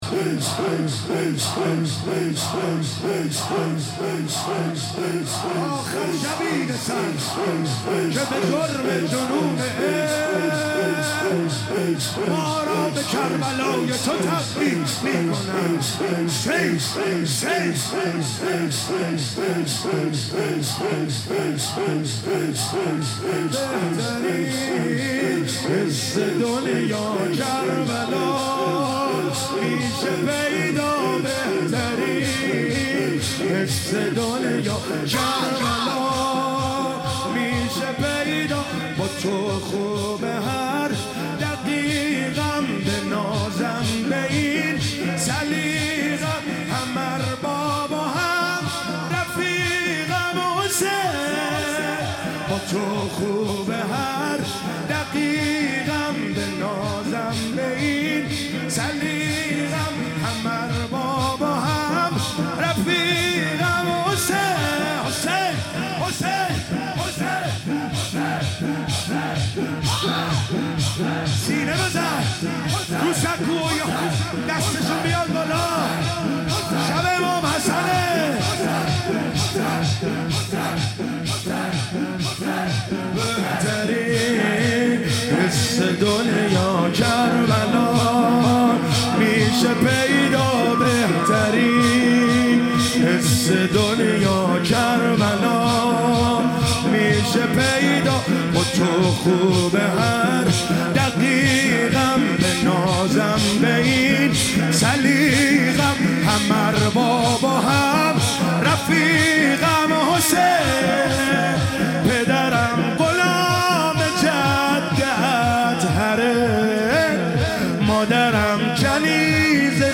مداحی
در شب ششم محرم